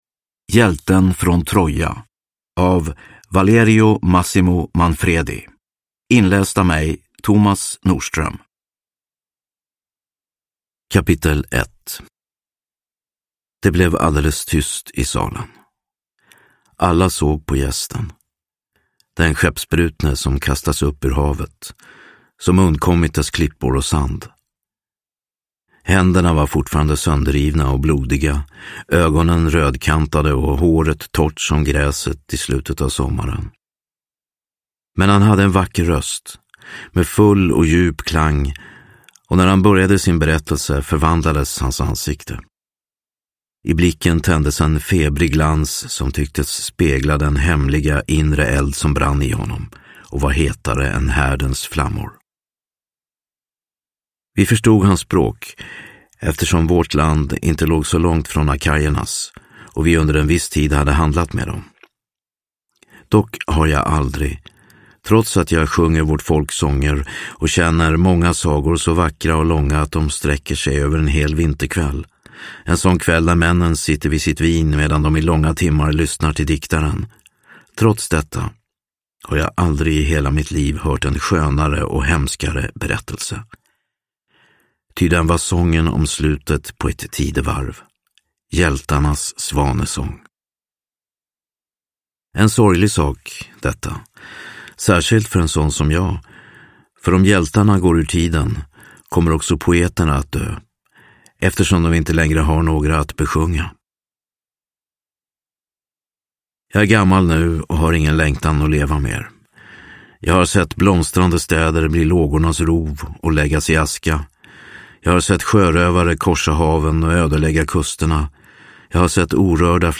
Hjälten från Troja – Ljudbok – Laddas ner